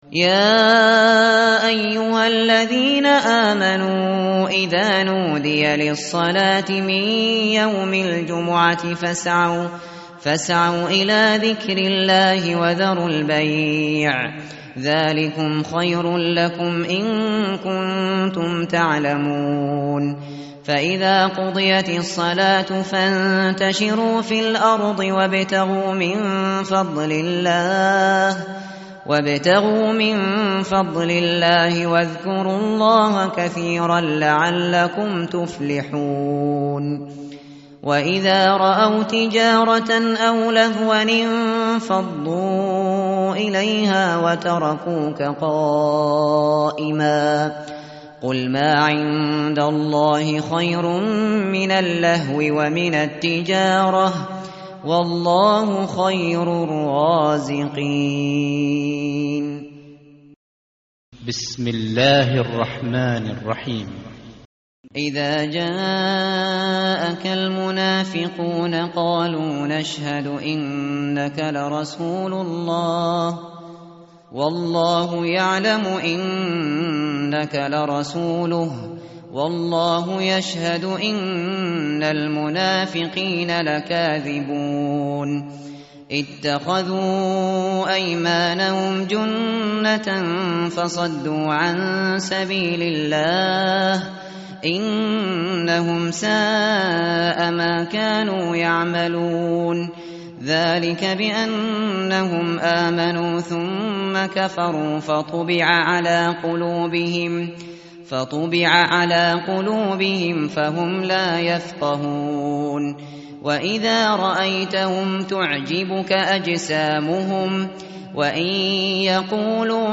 متن قرآن همراه باتلاوت قرآن و ترجمه
tartil_shateri_page_554.mp3